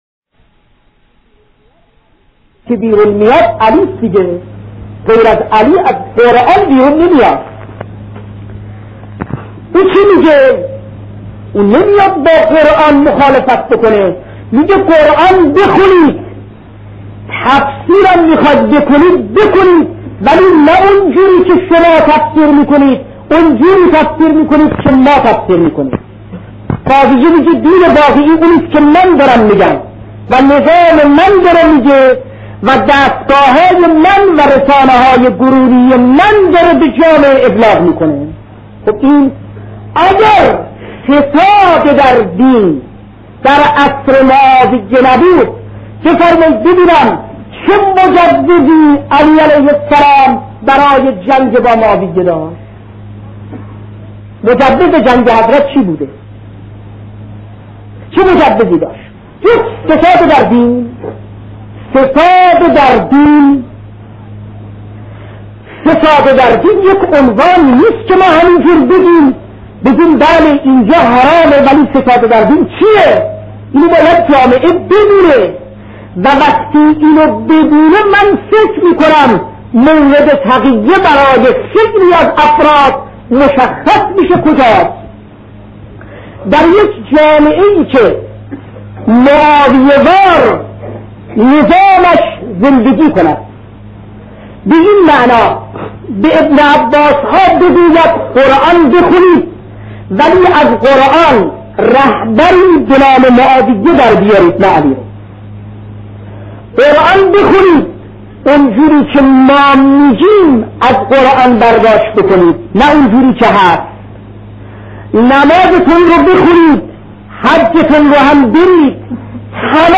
بخش بیست و ششم سخنرانی شهید حجت الاسلام هاشمی نژاد در موضوعیت بررسی مکتب